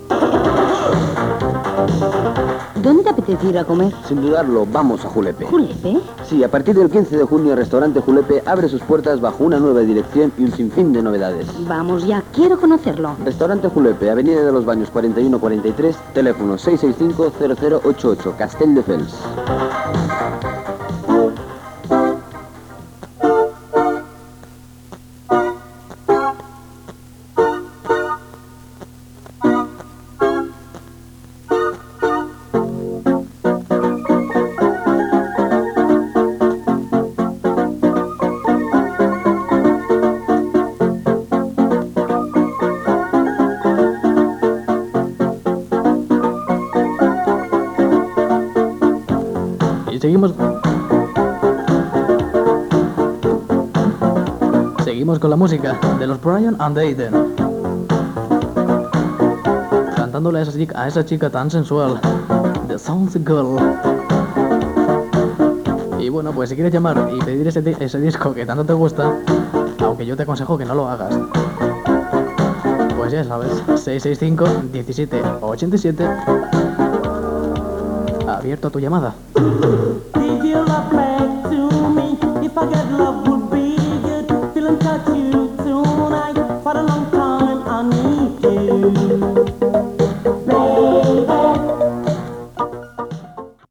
c90f76717c878d087c9cc35900ff4f3bd2f1e43d.mp3 Títol Radio Aquí Emissora Radio Aquí Titularitat Tercer sector Tercer sector Musical Descripció Publicitat, telèfon i tema musical.